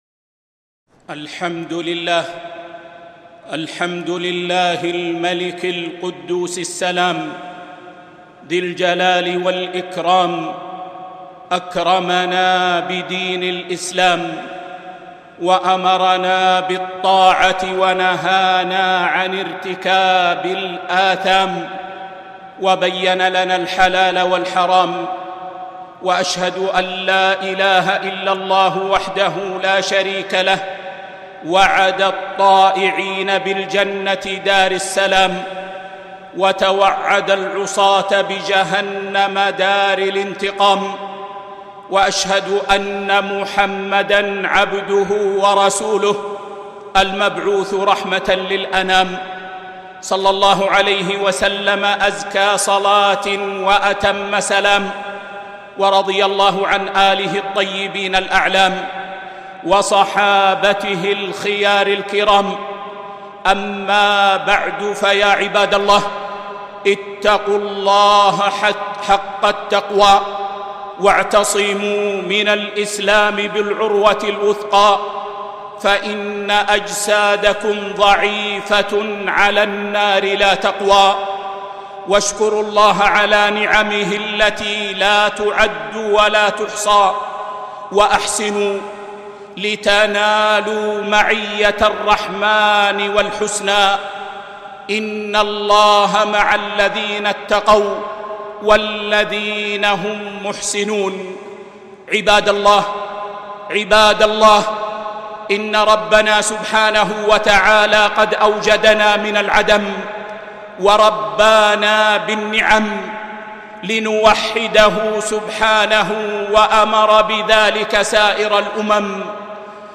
خطبة - الصبر وقرار الحج 5 ذي القعدة 1441 هــ